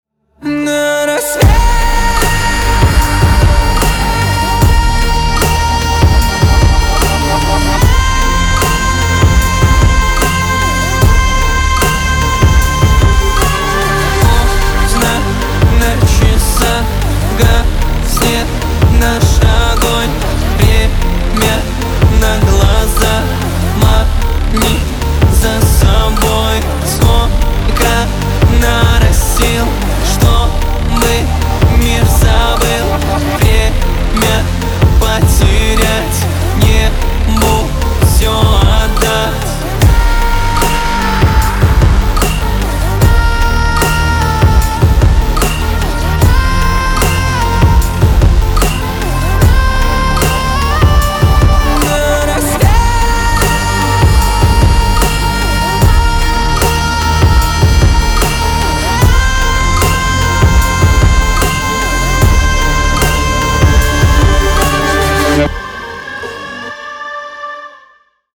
• Качество: 320, Stereo
поп
мужской вокал
мощные
атмосферные
качающие